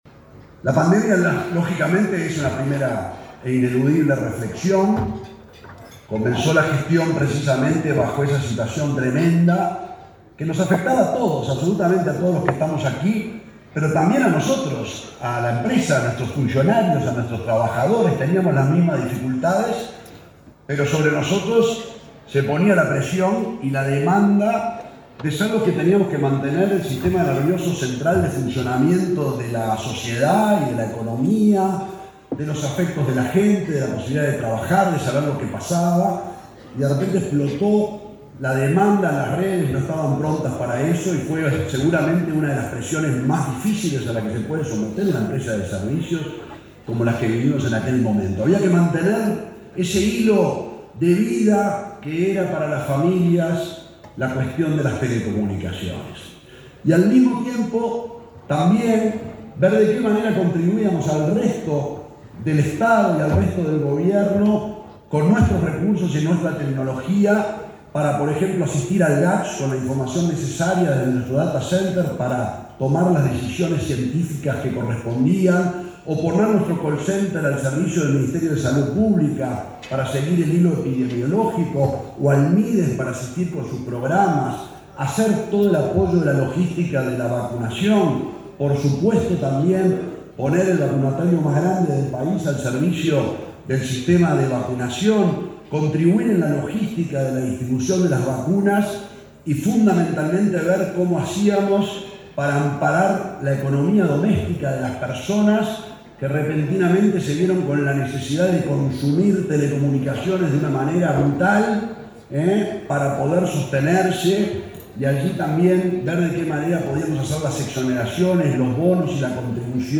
Disertación del presidente de Antel, Gabriel Gurméndez
Disertación del presidente de Antel, Gabriel Gurméndez 12/10/2023 Compartir Facebook X Copiar enlace WhatsApp LinkedIn El presidente de Antel, Gabriel Gurméndez, disertó, este jueves 12 en Montevideo, en un almuerzo de trabajo de la Asociación de Dirigentes de Marketing.